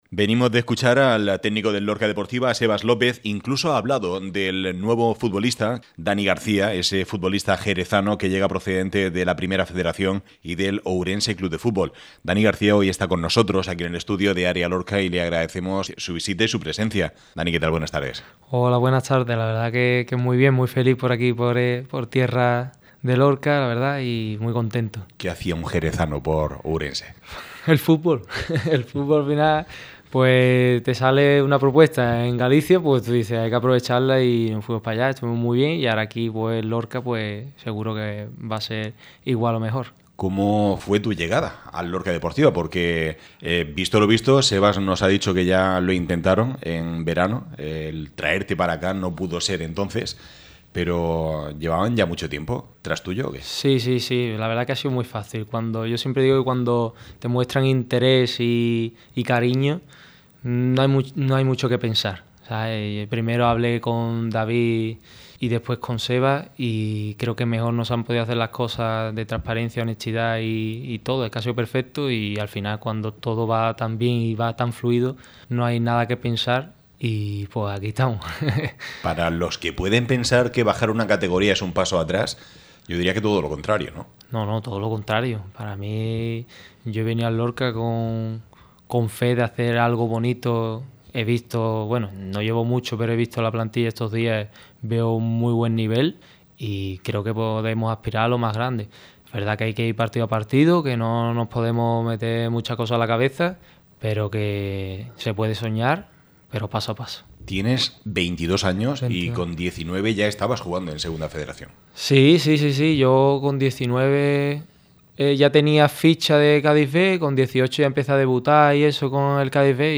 entrevista
en el estudio de Área Lorca Radio en el transcurso del programa deportivo